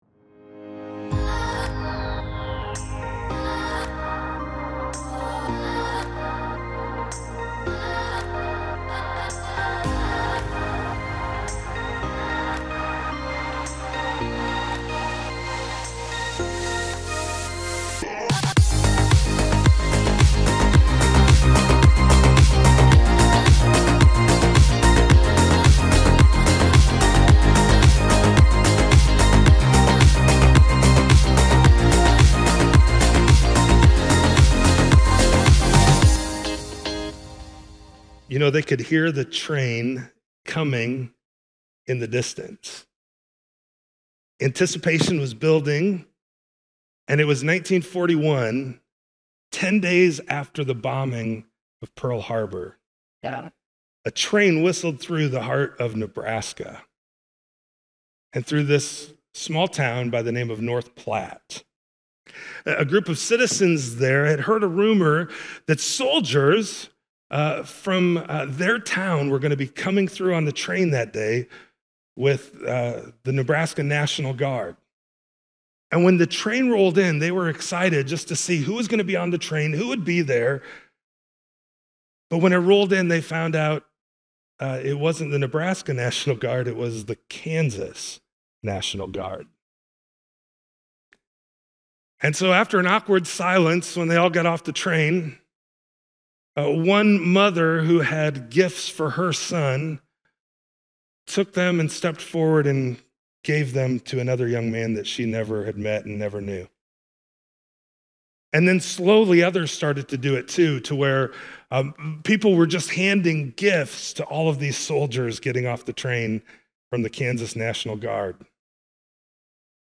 Part of that message plays at the end of this sermon.)